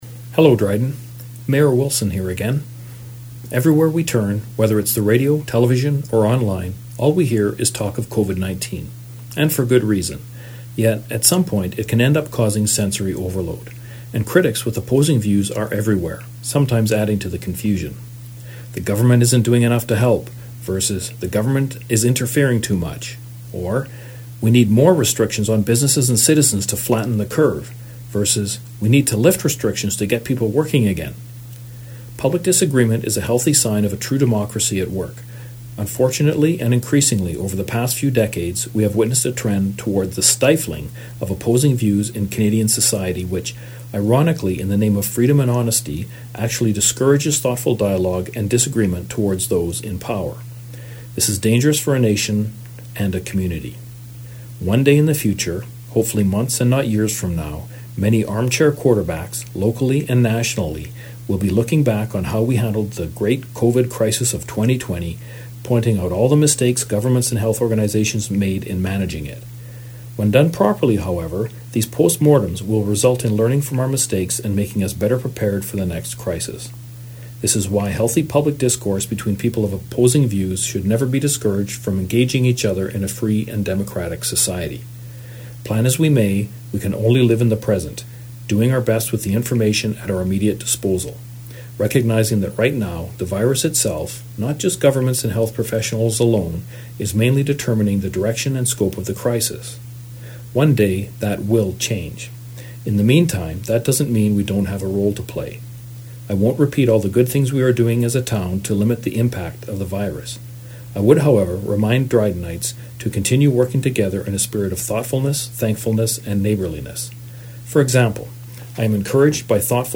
Audio: Weekly Statement From Mayor Greg Wilson
Greg Wilson addressed one positive move during his weekly address on CKDR News.